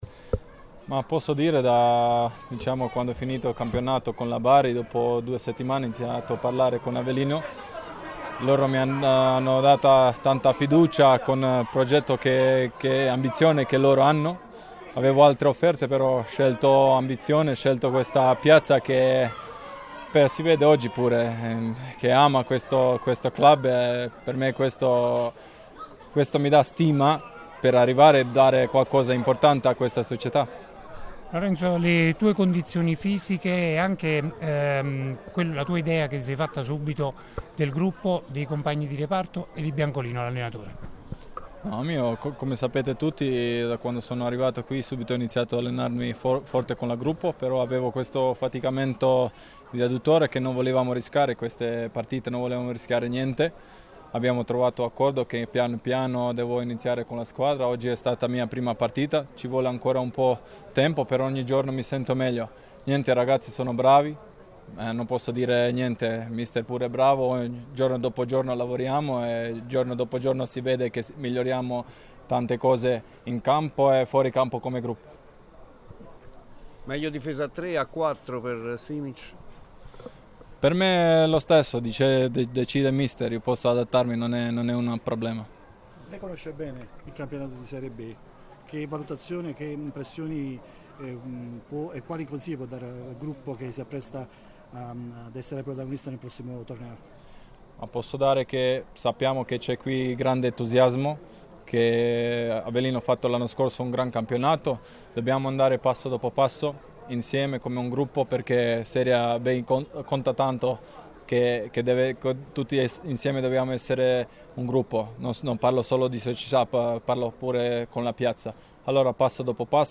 Prima conferenza stampa